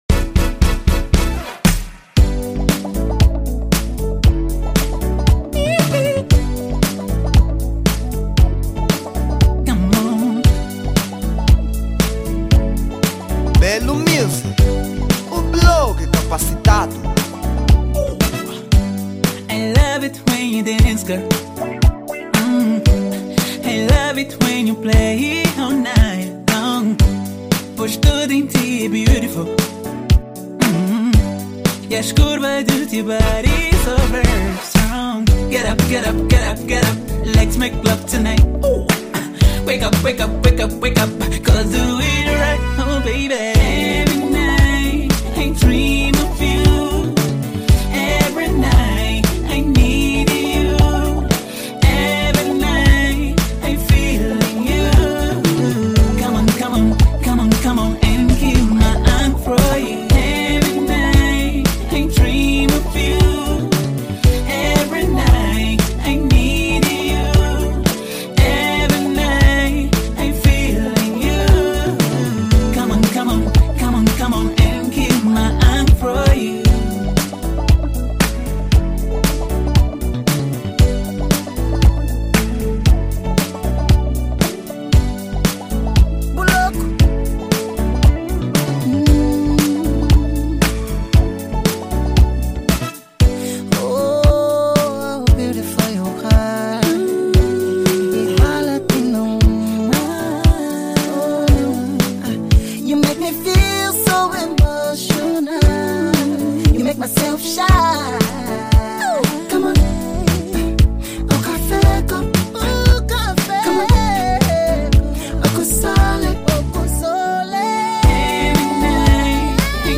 Género : Pop